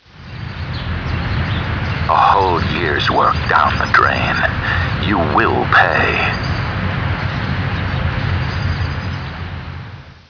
THE LONDON SUN & NEWS OF THE WORLD's confidential sources have discovered that a series of threatening and possibly revealing telephone calls are being received from a source or sources unknown who apparently have information on the whereabouts of Meg Townsend.
Written transcripts of audio clues (TXT files) are available for users without sound-cards -- but where possible, we recommend downloading the sound files, because the background sounds and audio subtleties can be helpful in formulating your theory!